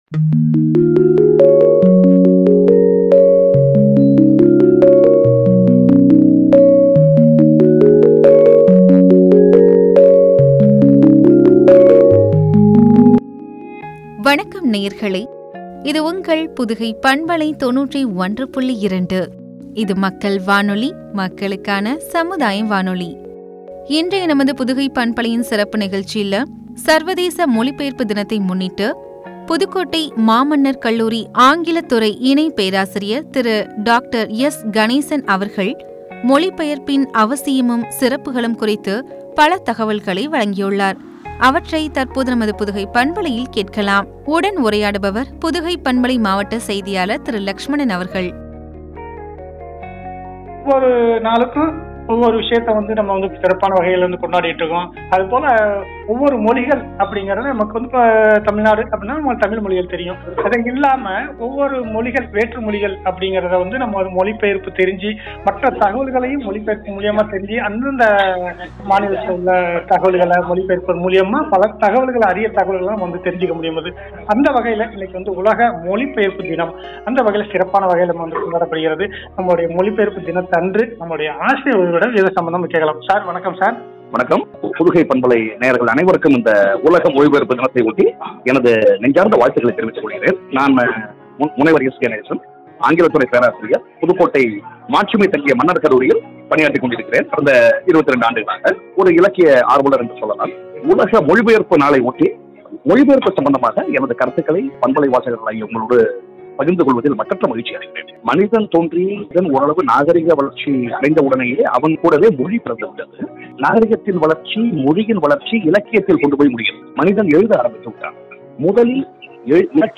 மொழிபெயர்ப்பின் அவசியமும்,சிறப்புகளும் பற்றிய உரையாடல்.